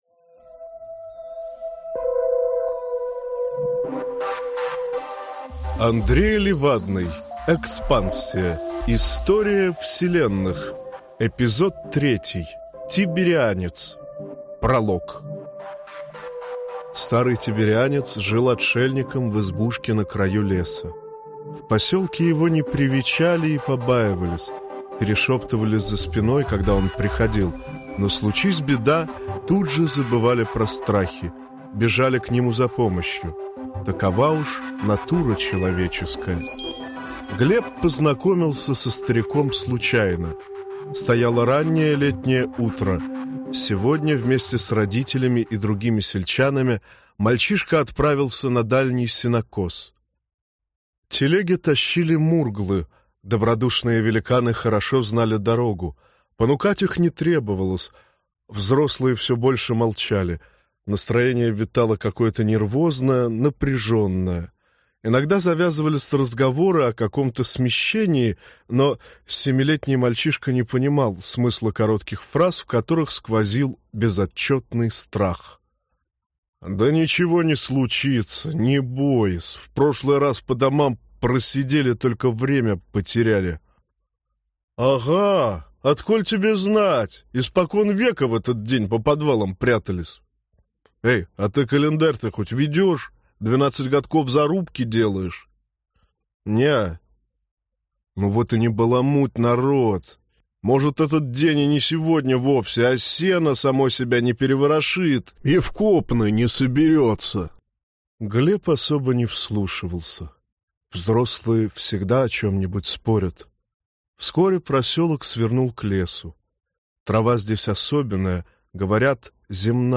Аудиокнига Тиберианец | Библиотека аудиокниг